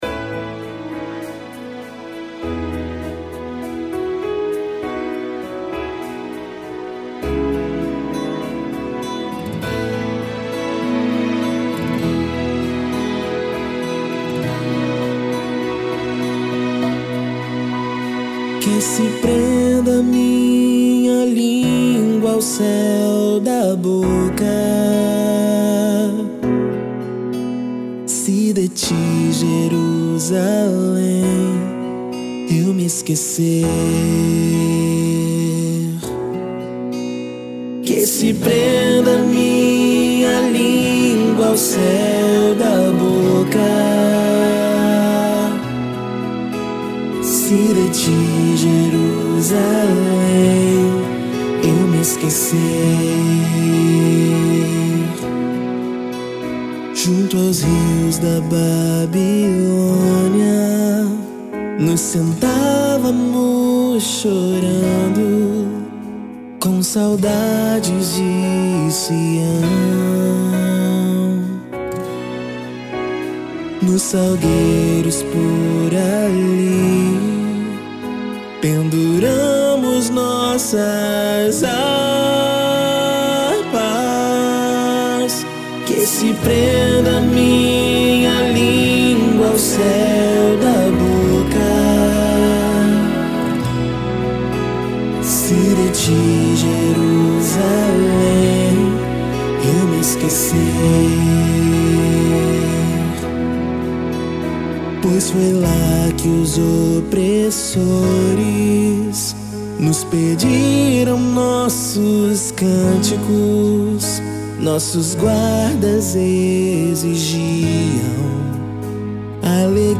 4º Domingo da Quaresma
Backing Vocal